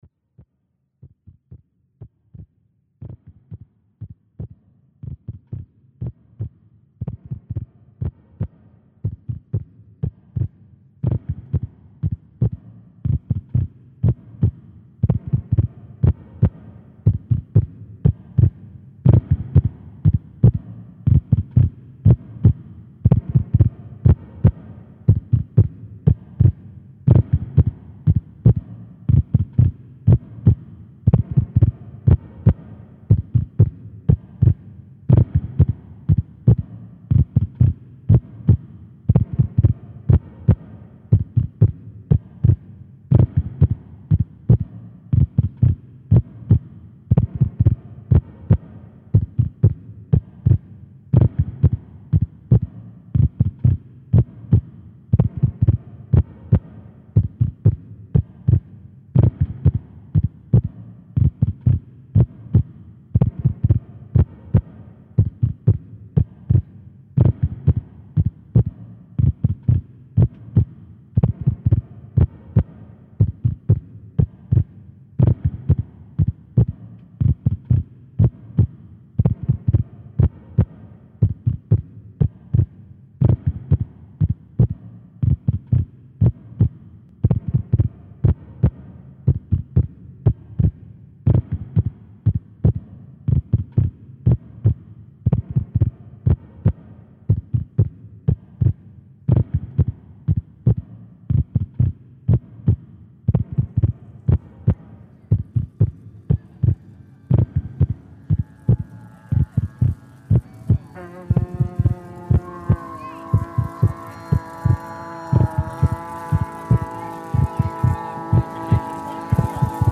This iteration for the roaming arts organisation aims to essay on the ideas, themes and subjects surrounding the act of wandering, articulated through narratives, song, sound enquiry and monologue.
The podcast and launch will be framed within a monologue narrative, 4 chapters in length.